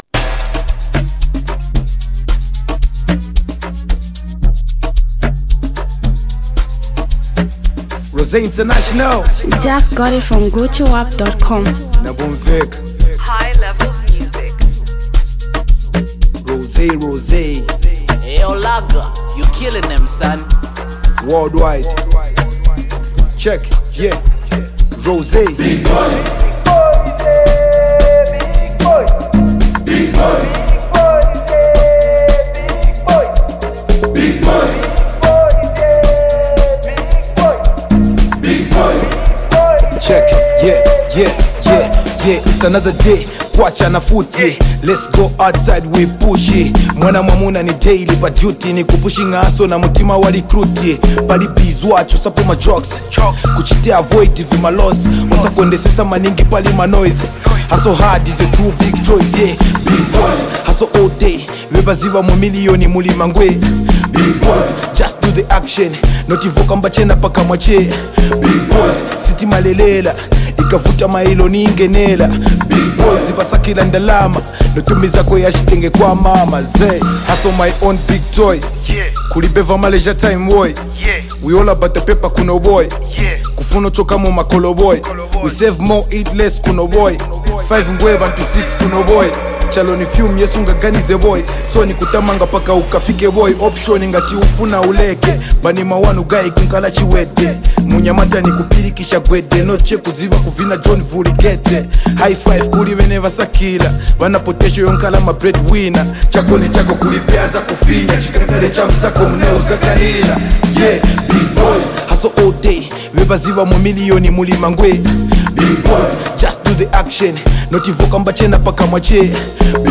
rap
buzzing street hit anthem record